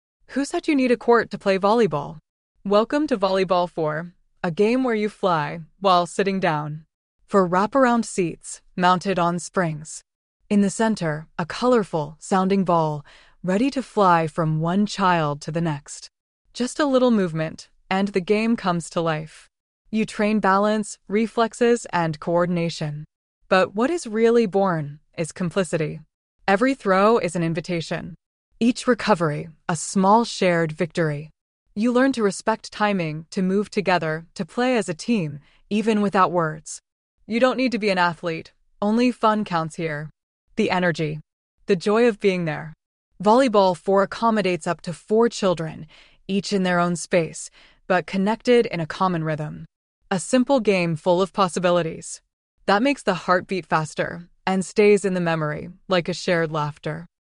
By rocking on the spring seats, children can play with each other by tossing a colorful, sound-producing ball that is attached to a rotating arm.